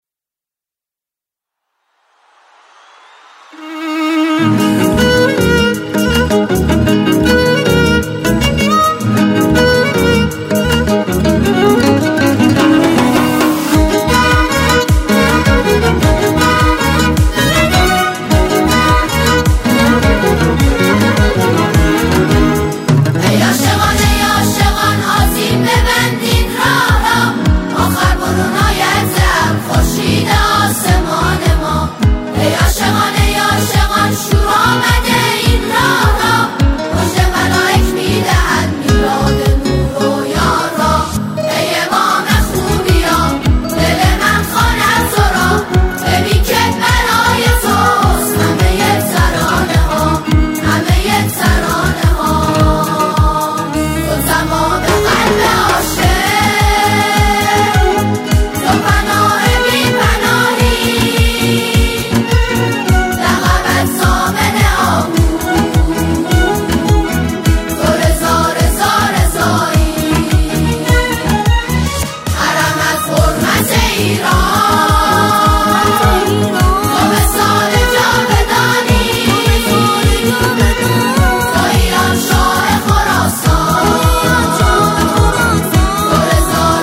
سرودهای امام رضا علیه السلام